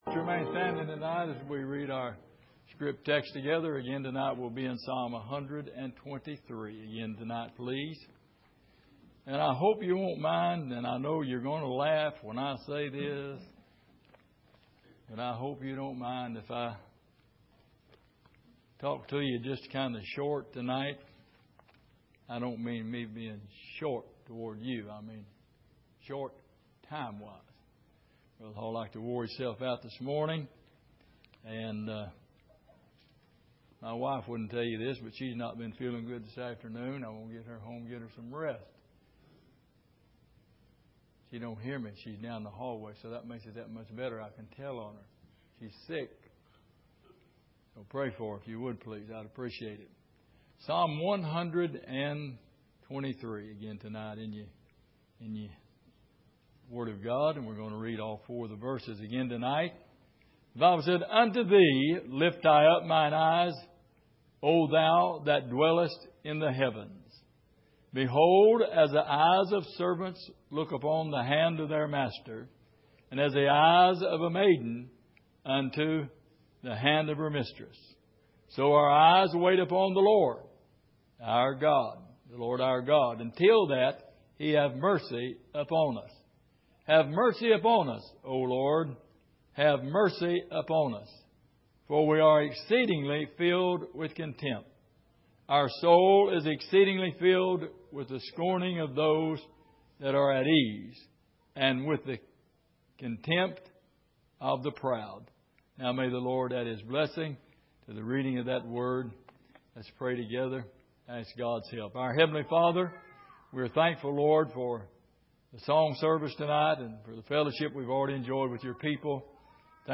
Exposition of the Psalms Passage: Psalm 123:1-4 Service: Sunday Evening What Kind Of Heart Do You Have?